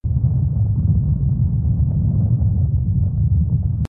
tt_s_ara_cmg_groundquake.ogg